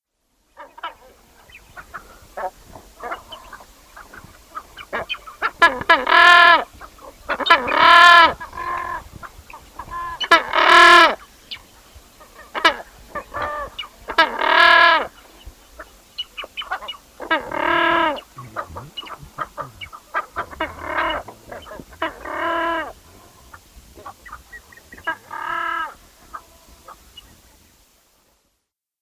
CAUQUEN COMÚN
cauquen.wav